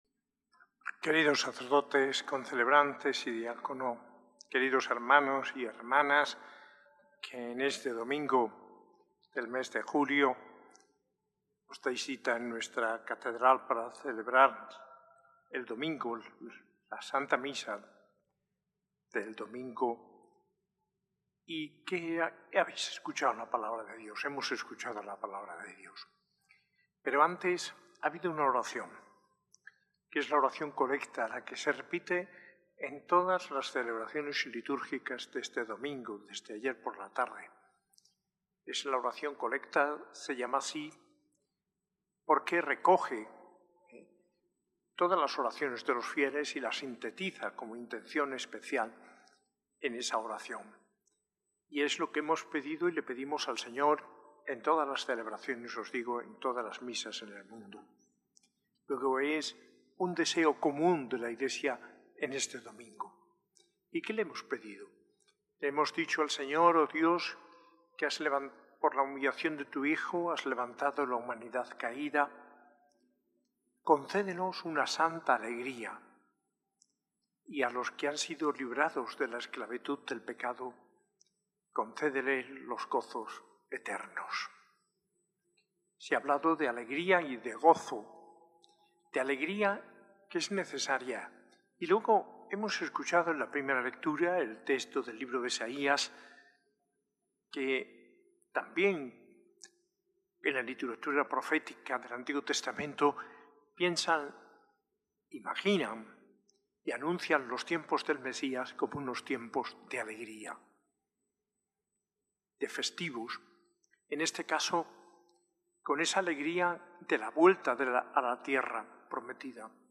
Homilía en la Eucaristía del domingo XIV del Tiempo Ordinario, por el arzobispo Mons. José María Gil Tamayo, el 6 de julio de 2025.